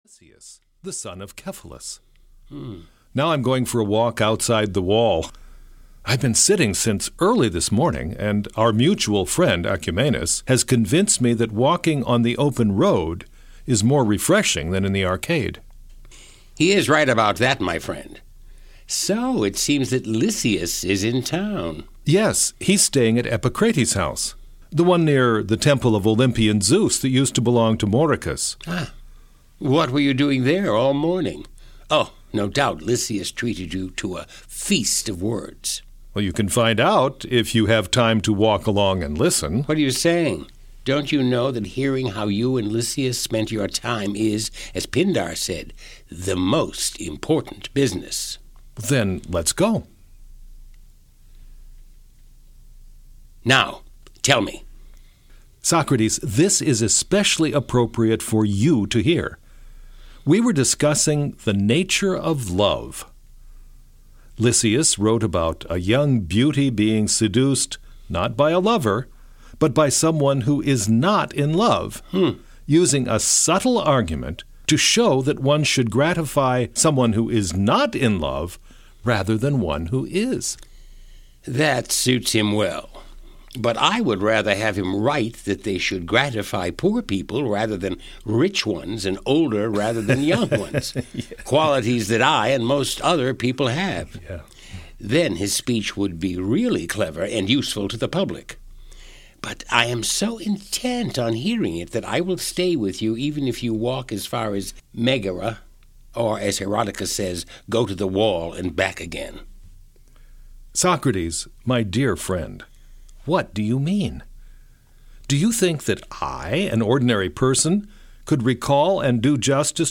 Plato’s Phaedrus (EN) audiokniha
Ukázka z knihy